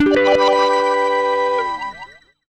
GUITARFX 3-R.wav